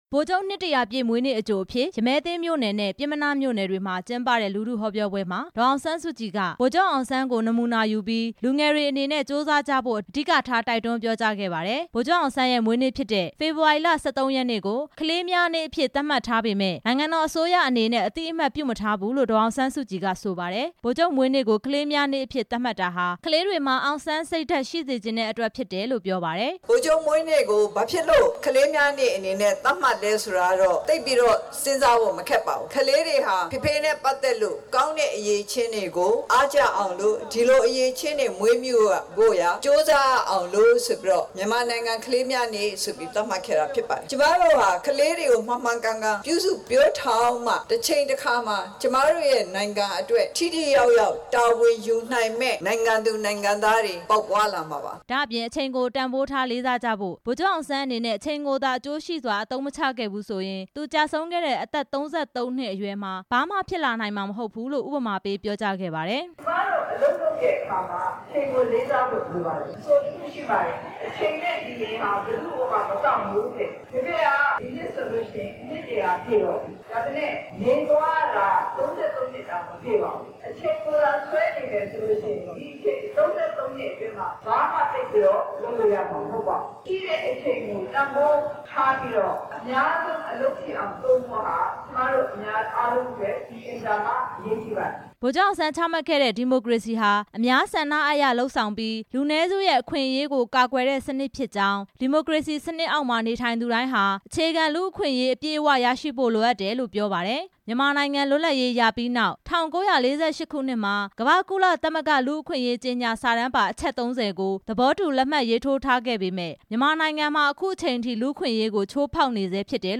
မြန်မာနိုင်ငံမှာ လူ့အခွင့်အရေး ချိုးဖောက်မှုတွေရှိနေ ဆဲဖြစ်တယ်လို့ အမျိုးသားဒီမိုကရေစီအဖွဲ့ချုပ် ဥက္ကဌ ဒေါ်အောင်ဆန်းစုကြည်က ဒီနေ့ မန္တလေး တိုင်းဒေသကြီး ရမည်းသင်းမြို့နယ်မှာ ကျင်းပတဲ့ ဗိုလ်ချုပ်အောင်ဆန်း နှစ်တစ်ရာပြည့် မွေးနေ့အကြို လူထုဟောပြောပွဲမှာ ပြောပါတယ်။